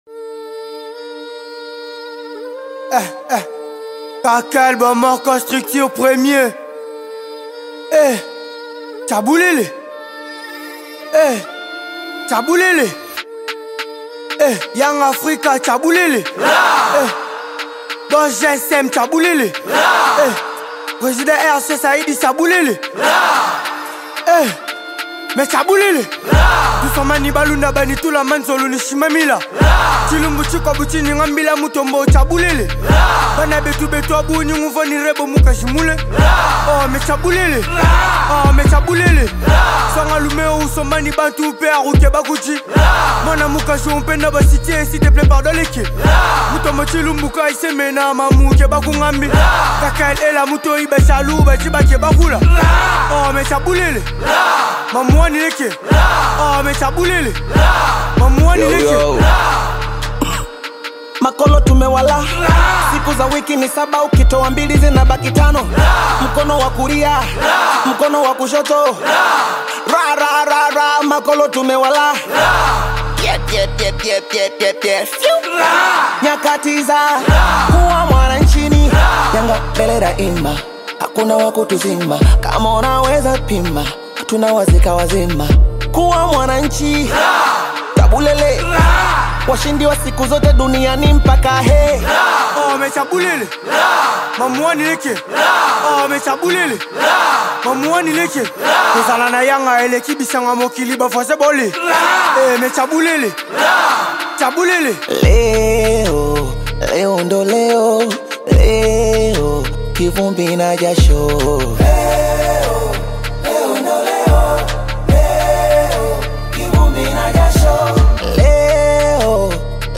spirited anthem
vibrant vocals
resonates with energetic and anthemic qualities